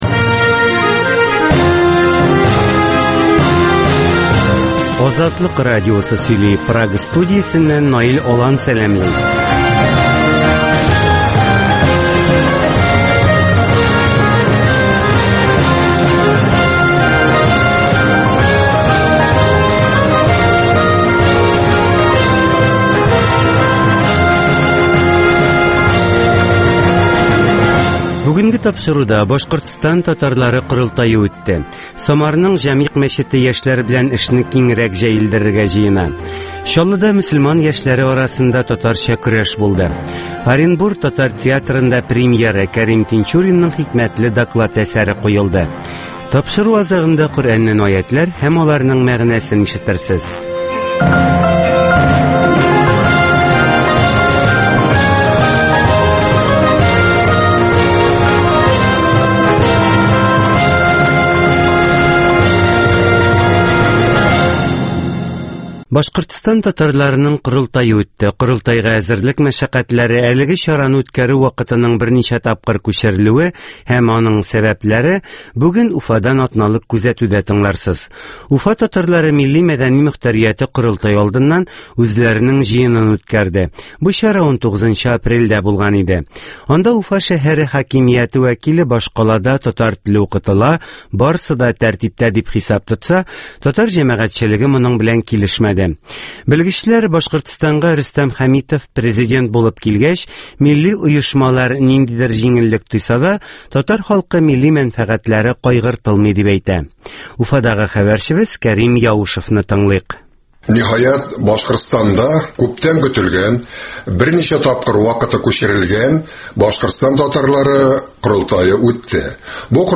якшәмбе тапшыруы (кабатлау)